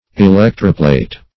Electroplate \E*lec"tro*plate`\, v. t. [imp. & p. p.